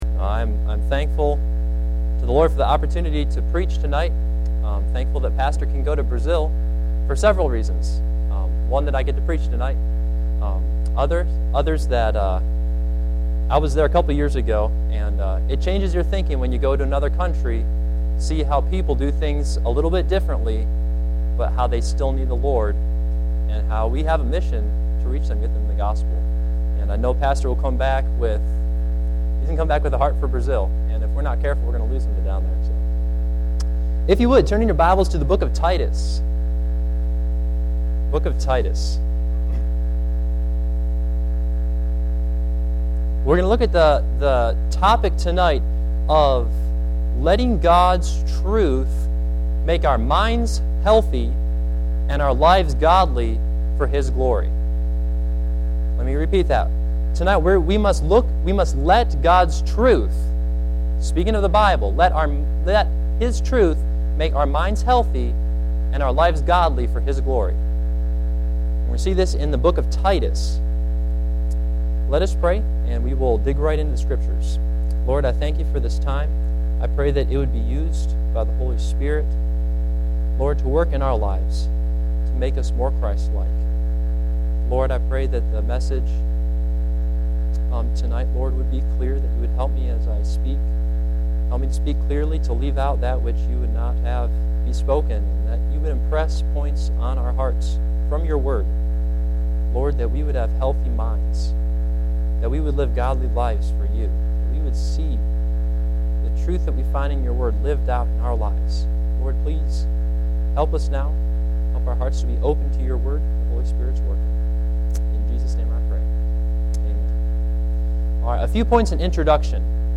Titus 1-2 Service Type: Sunday PM Bible Text